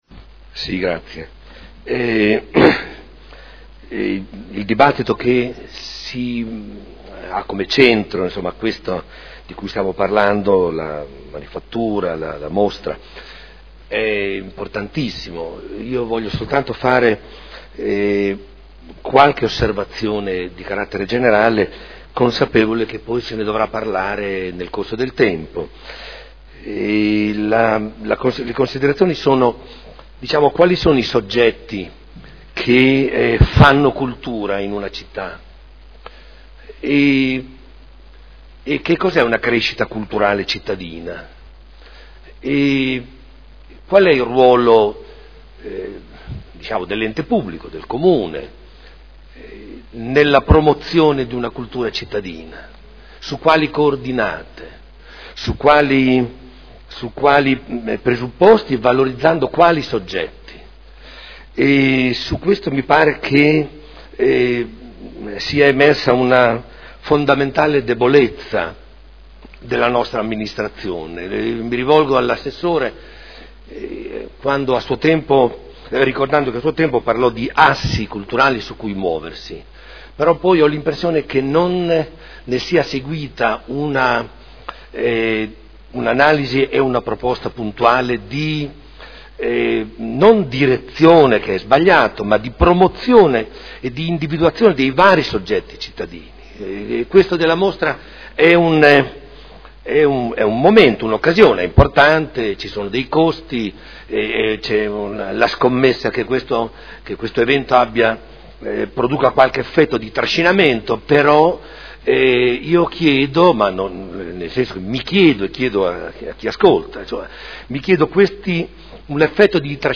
Domenico Campana — Sito Audio Consiglio Comunale
Seduta del 09/07/2015 Dibattito. Interrogazioni 81876, 83091, 85381 presentate da Scardozzi e Galli su Manifattura e mostre.